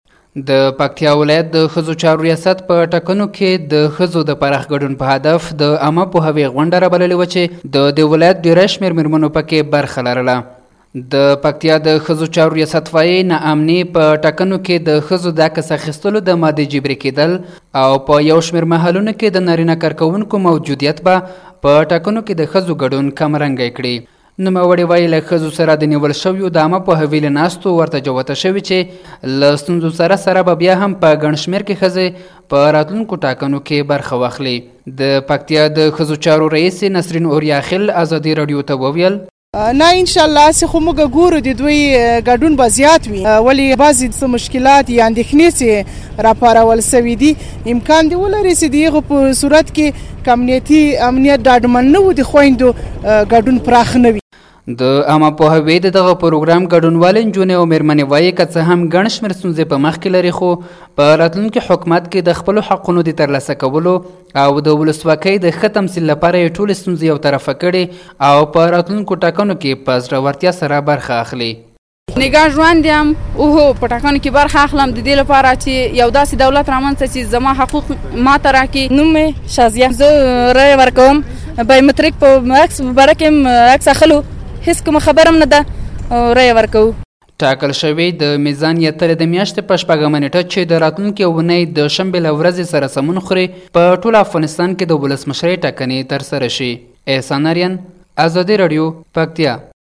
د پکتیا راپور